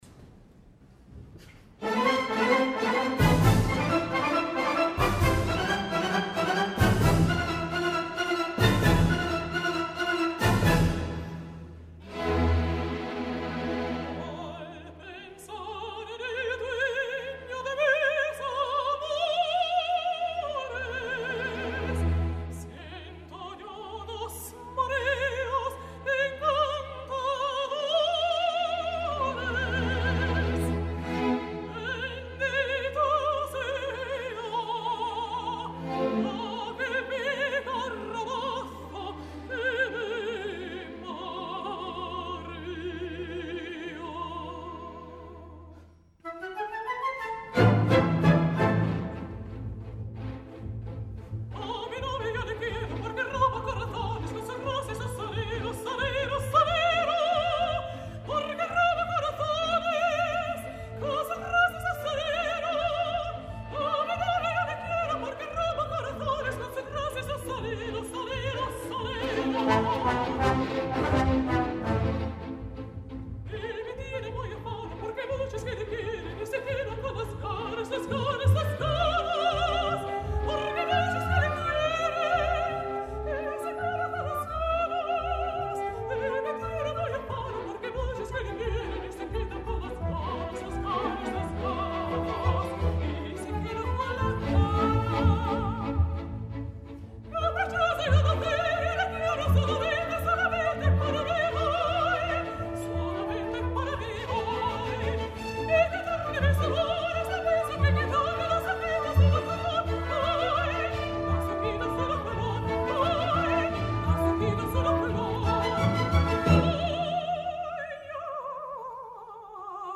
al Victoria Hall de Ginebra